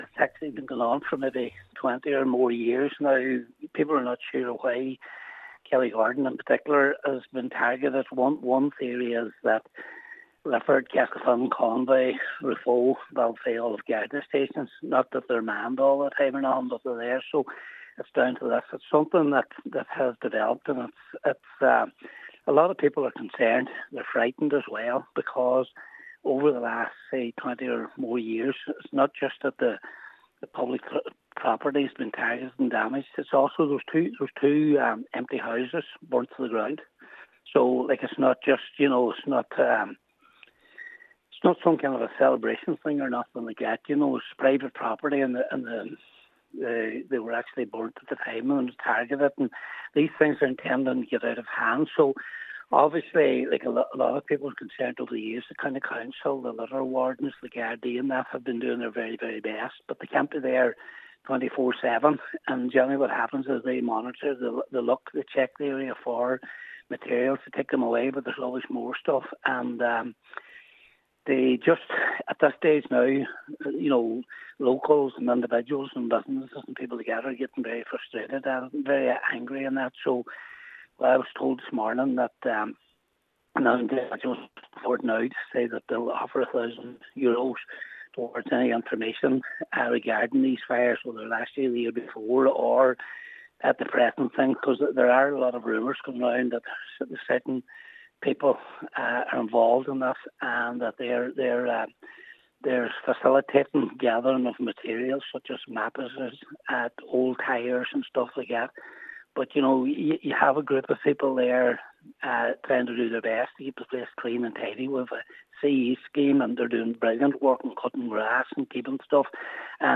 Councillor Patrick McGowan says there is a lot of anger and frustration among the local community: